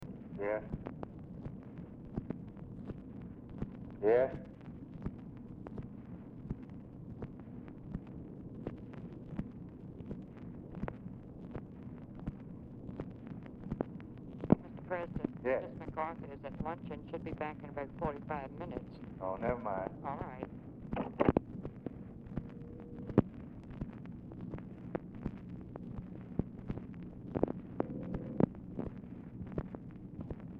Telephone conversation # 2197, sound recording, LBJ and TELEPHONE OPERATOR, 2/25/1964, time unknown | Discover LBJ